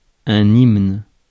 Ääntäminen
France: IPA: [imn]